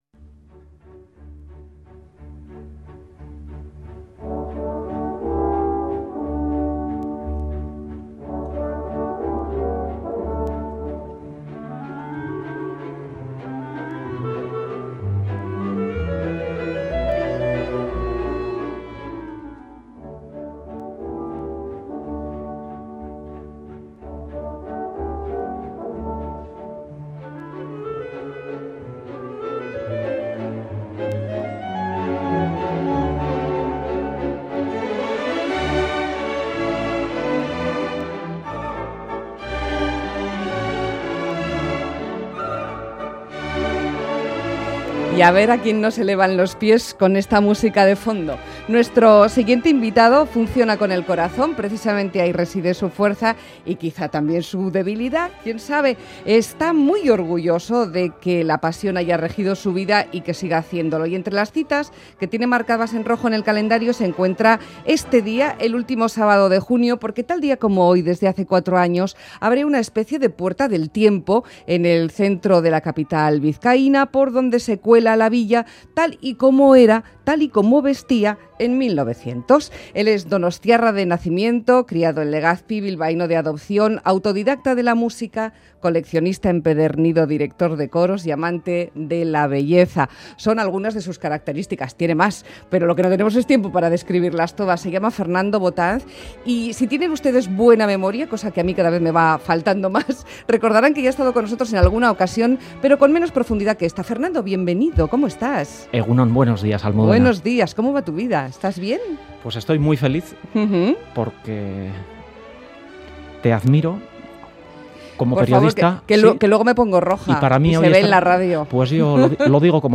Nos trasladamos a 1900 en nuestra entrevista personal.